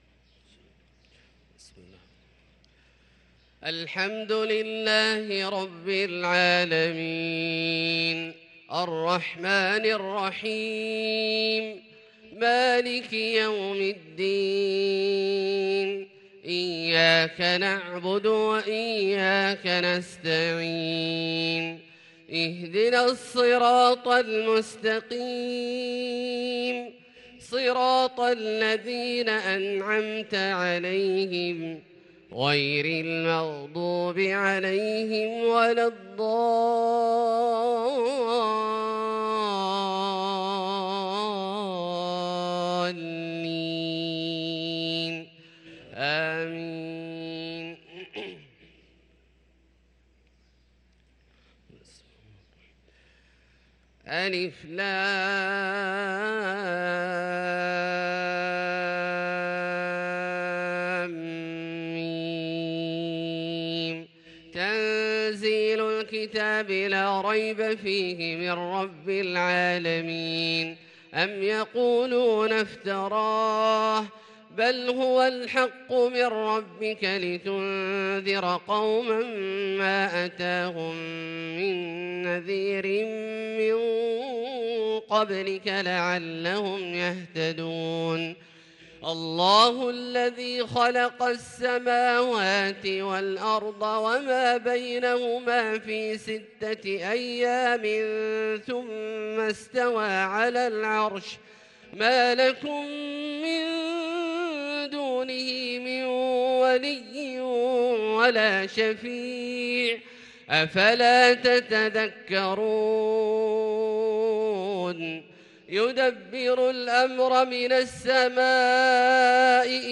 صلاة الفجر للقارئ عبدالله الجهني 20 صفر 1444 هـ
تِلَاوَات الْحَرَمَيْن .